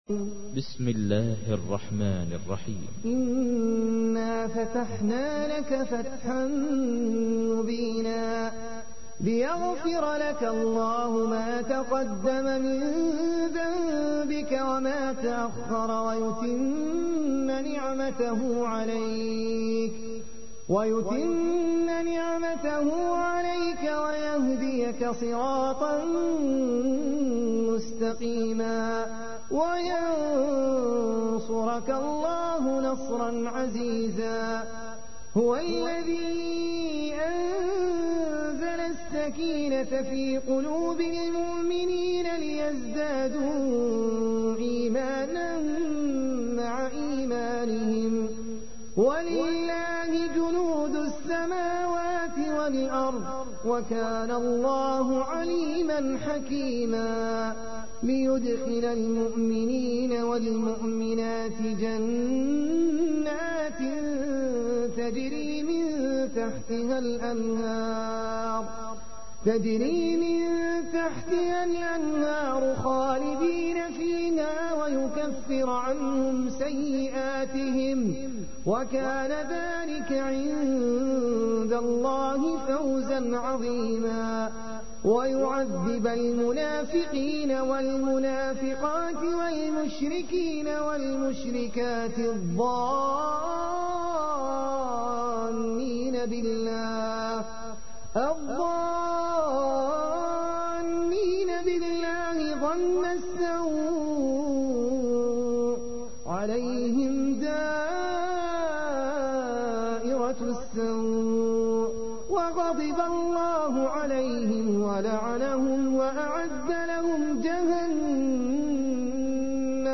تحميل : 48. سورة الفتح / القارئ احمد العجمي / القرآن الكريم / موقع يا حسين